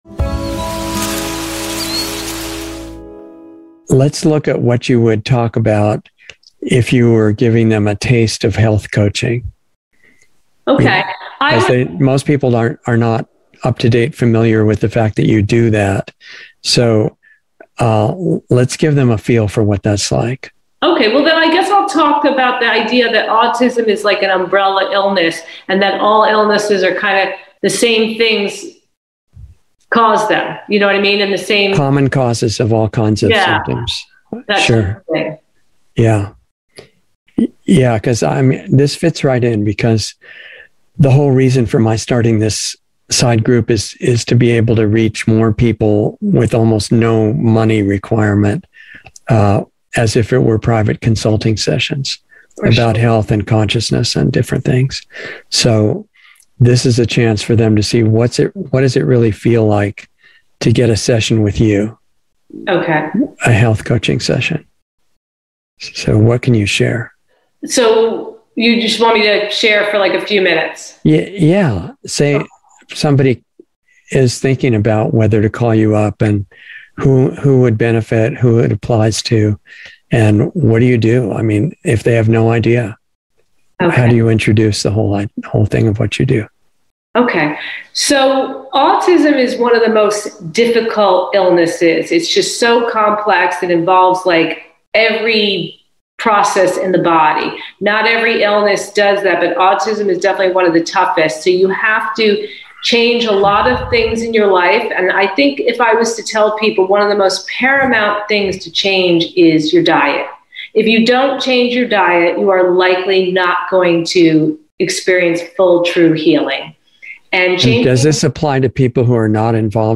Insider Interview 5/26/22